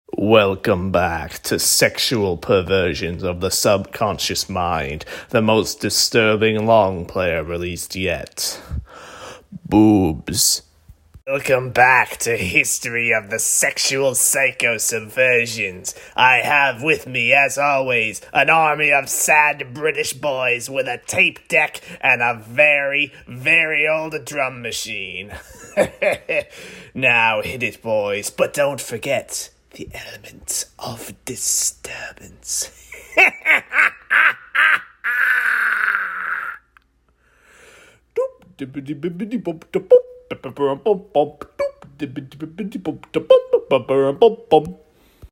DIY bedroom pop